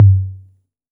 TR 808 Tom 01.wav